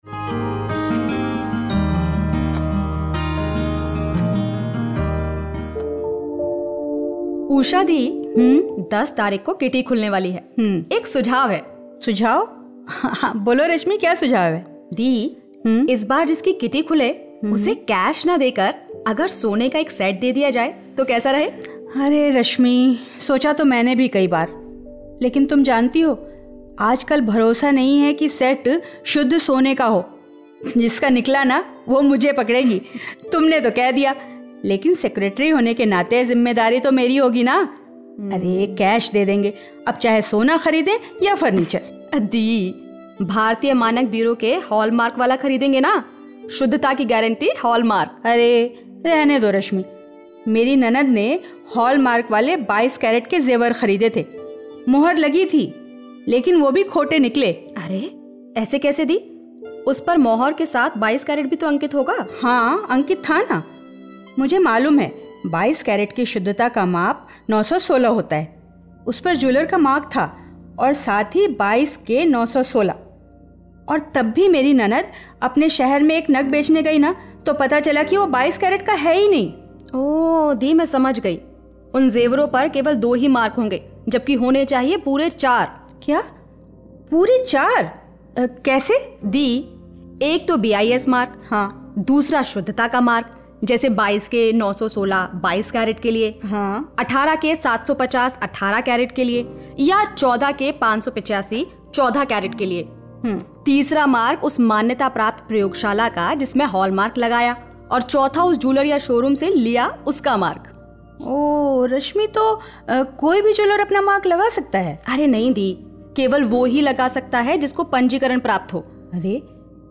Skit on promotion of Hallmark.